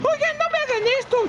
Worms speechbanks
Traitor.wav